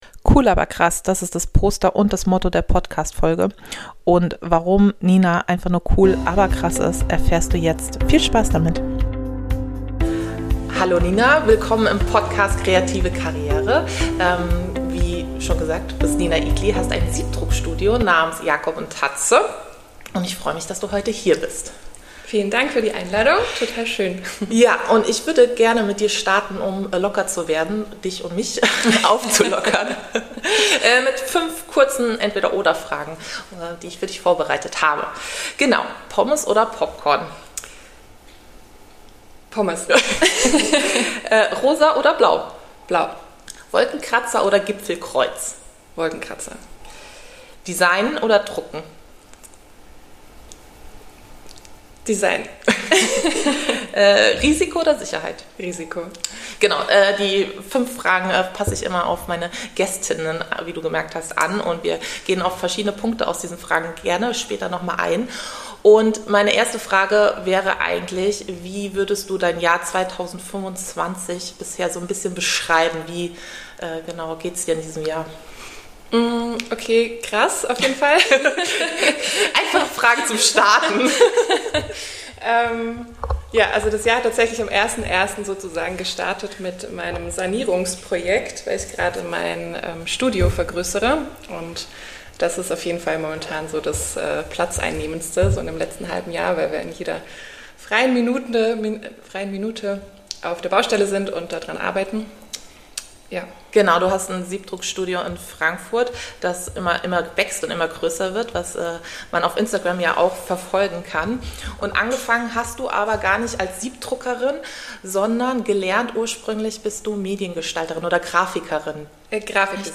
Ein inspirierendes Gespräch für alle, die mit Herzblut ein eigenes Projekt aufbauen wollen.